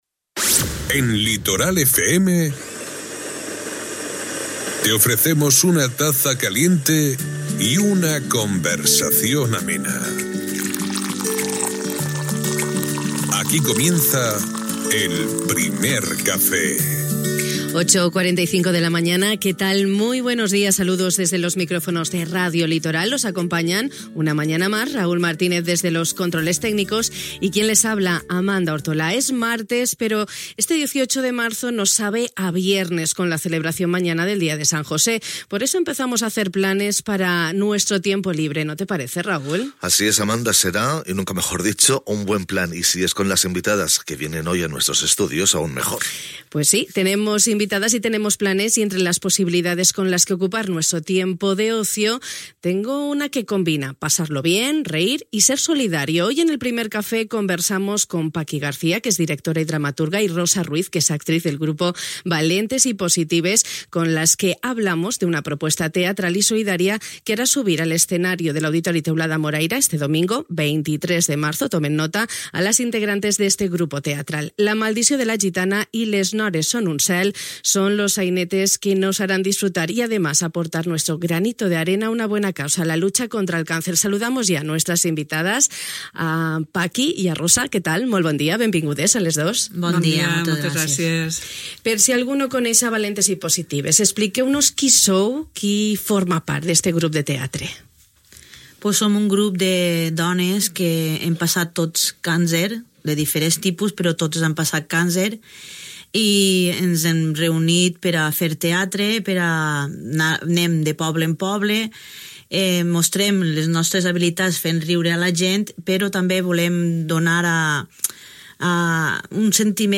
Nuestro Primer Café de hoy nos ha ayudado a hacer planes para el fin de semana. Hemos hablado de una propuesta en la que se combina pasarlo bien, reír y ser solidario.